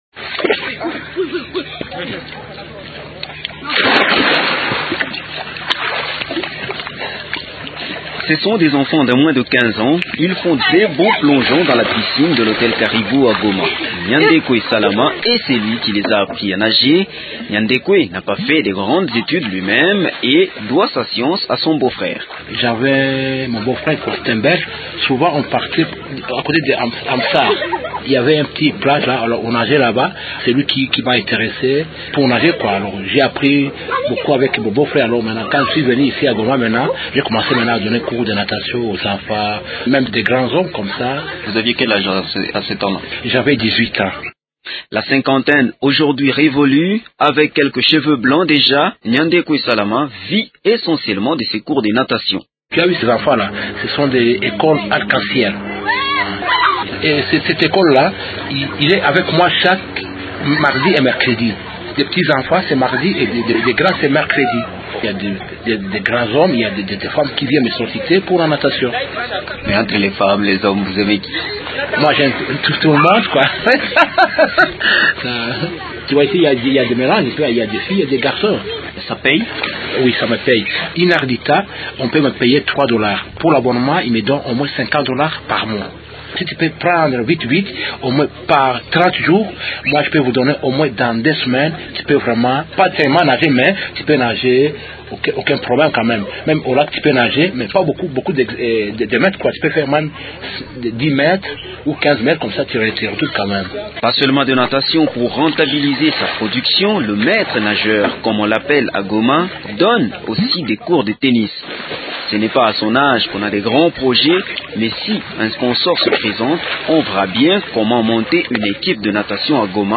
l’a rencontré dans un hôtel à Goma